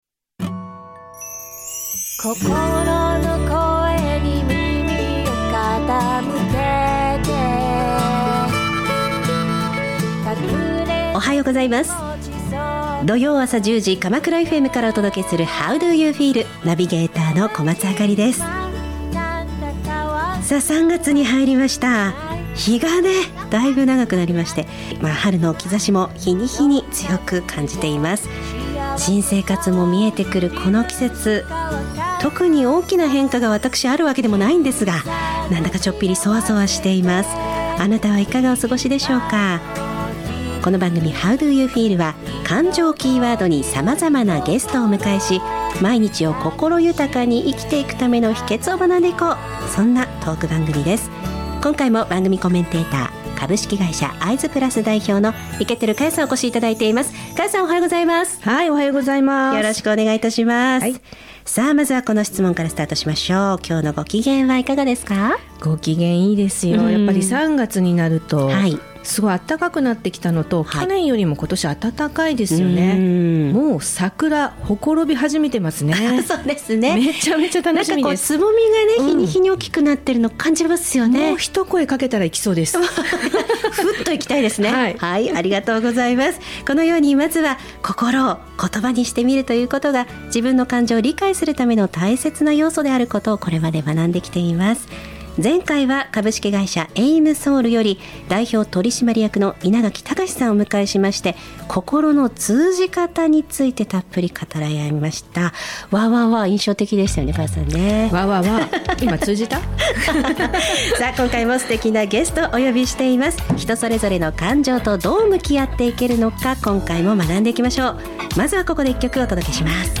番組では「感情知性=EQ」に注目!自身の感情を見つめ、心を豊かにするヒントを学んでいく、ゲストトーク番組です。